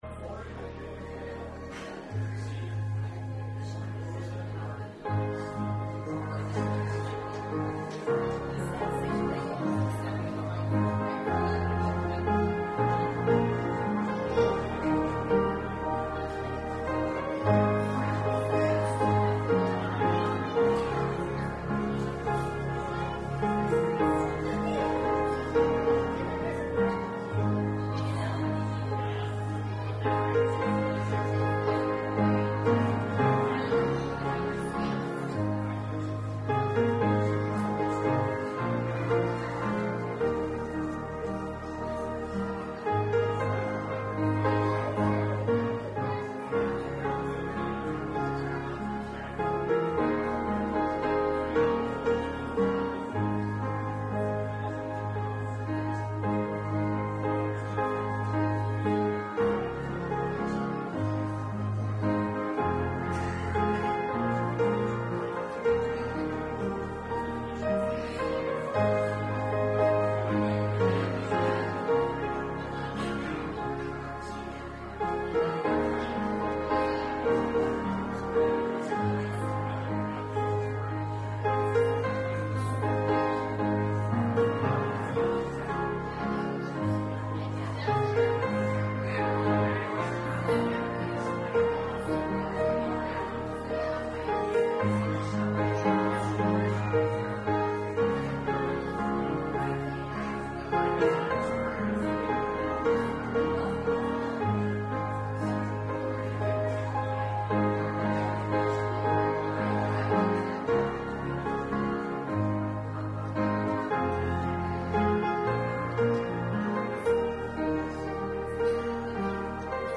Service Type: Sunday Morning Service Topics: Christian Living